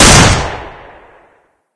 scar-fire.ogg